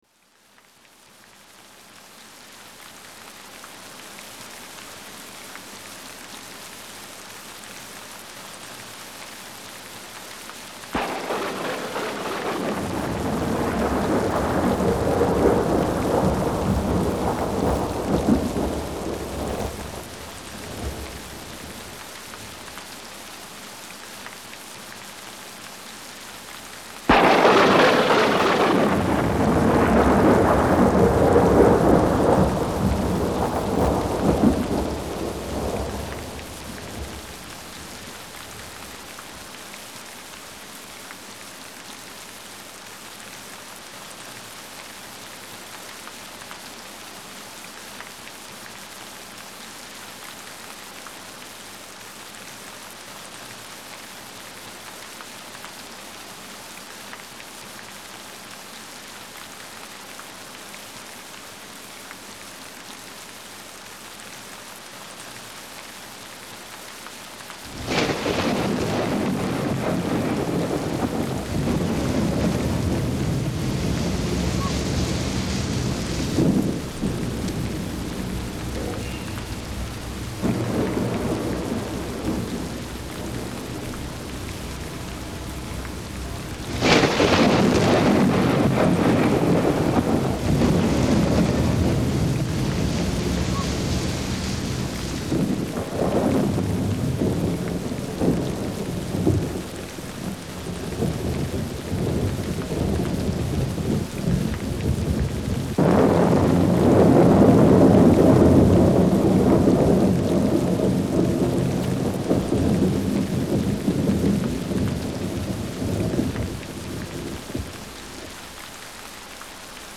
Гроза
Даже не верится,что эти звуки искусственные.